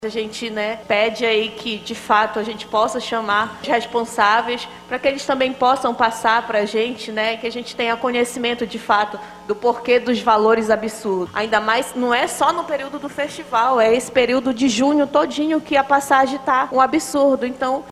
Deputados e deputadas estaduais da Assembleia Legislativa do Amazonas (Aleam) debateram em Sessão Plenária o alto preço das passagens aéreas para Parintins, no mês de junho, que despertou especial atenção dos parlamentares.
A deputada Mayra Dias (Avante) também fez questionamentos e cobranças sobre a alta no preço das passagens.